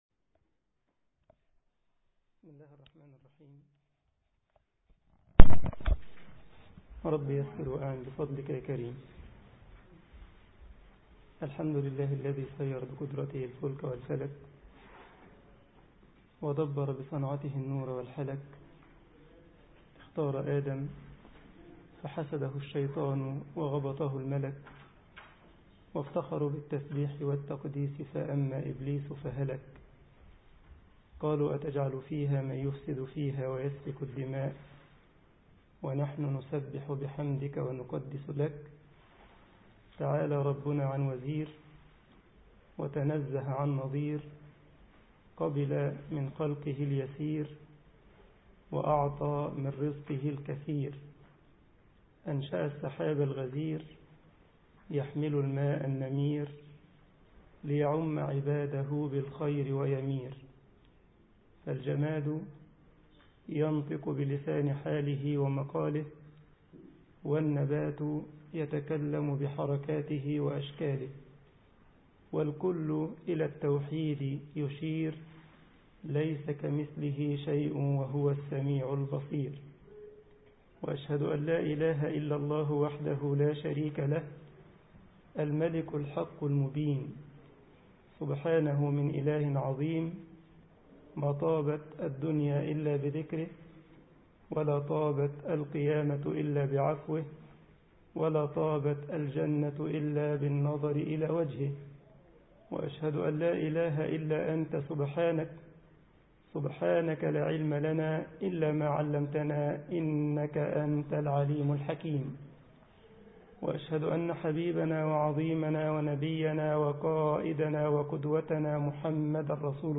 مسجد بمدينة بون ـ ألمانيا محاضرة